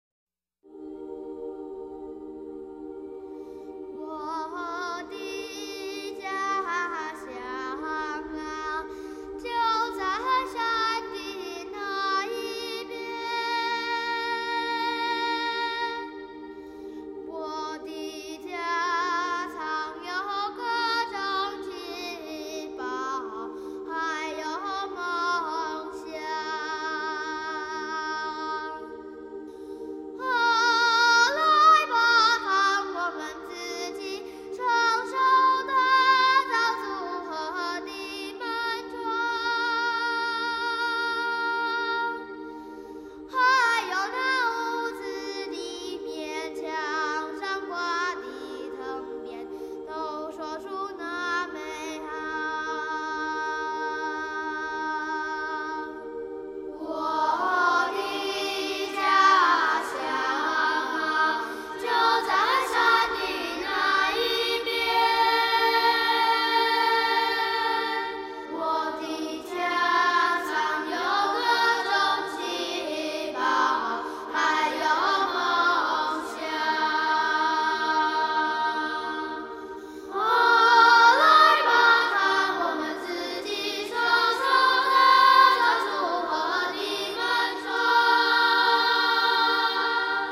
遇見另一角落的童聲吟唱
原住民青少年兒童合唱音樂的收錄珍
台中縣花東、自強新村原住民與平地小孩的聯合演唱